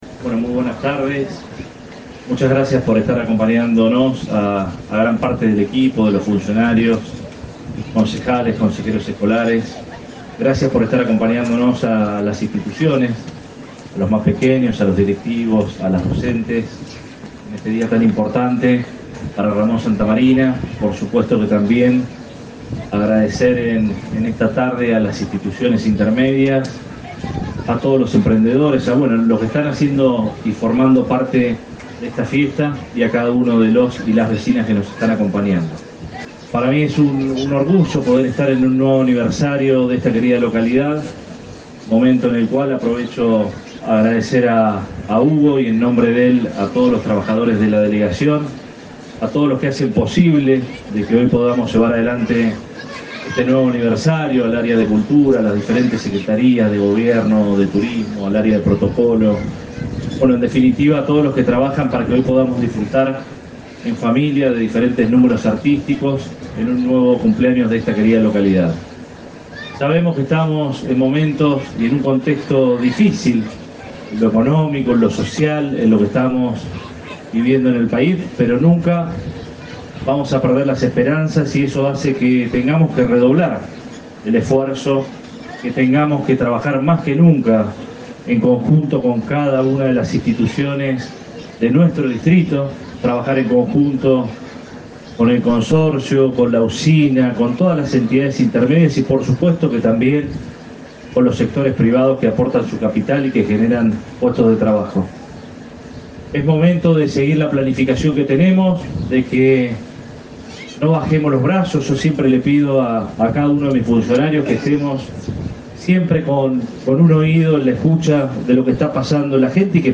El intendente Arturo Rojas encabezó las celebraciones en la plaza de la localidad, donde resaltó la obra de los pioneros del pueblo y llamó a fortalecer el trabajo en conjunto.